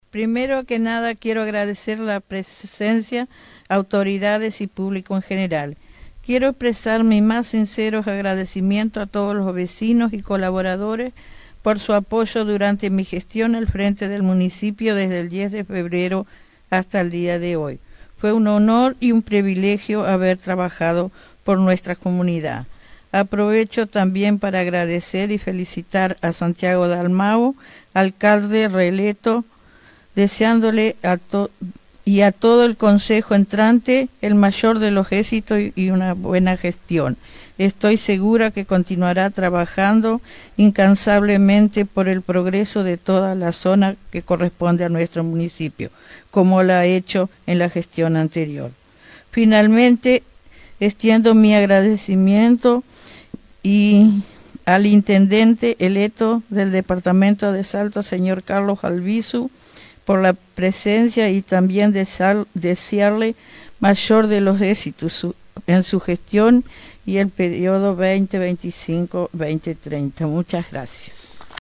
Este sábado, en el marco de una ceremonia que contó con la presencia de autoridades nacionales, departamentales y locales, Santiago Dalmao asumió nuevamente como alcalde del Municipio de Rincón de Valentín para el período 2025-2030, acompañado por los concejales que integrarán el nuevo Consejo Municipal.
La alcaldesa saliente, María Teresa Caballero, fue la encargada de abrir la oratoria. Agradeció profundamente el respaldo recibido durante su gestión y expresó su satisfacción por haber tenido la oportunidad de contribuir al desarrollo del municipio.